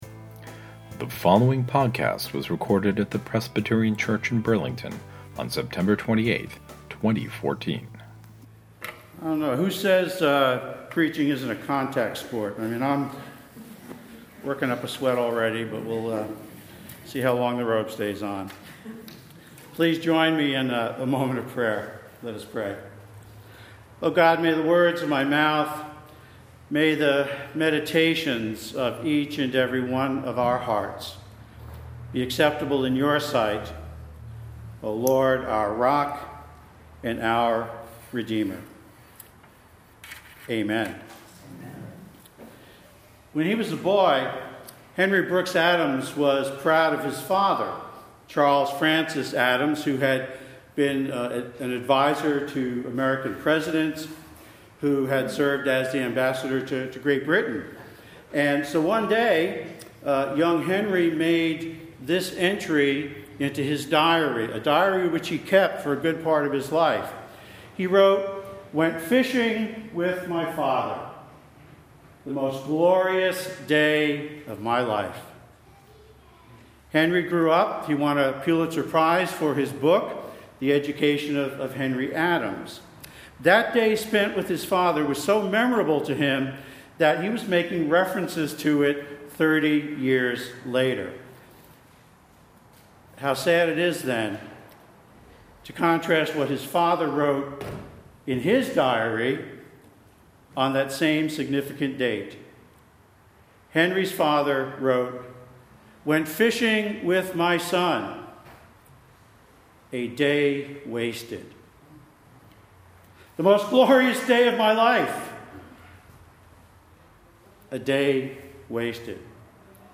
This Sunday’s sermon is entitled “A Second Chance”. The scripture is Acts 15: 36-41, which describes how Paul and Barnabas get into a major disagreement about John Mark.